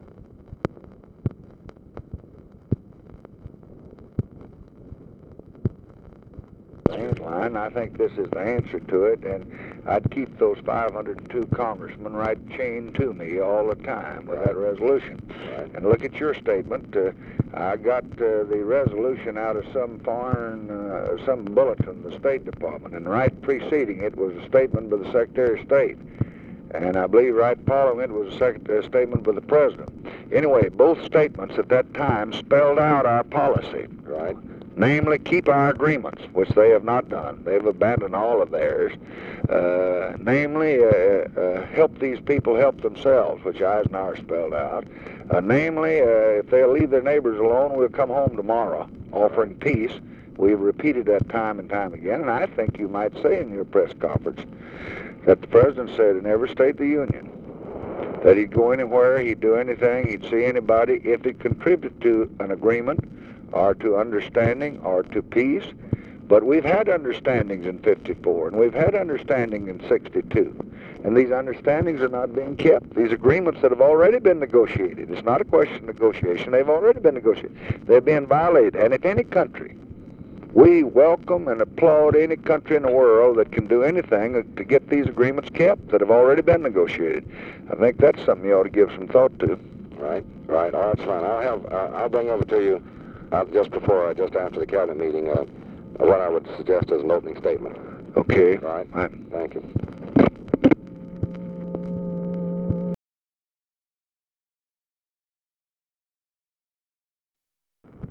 Conversation with DEAN RUSK, February 25, 1965
Secret White House Tapes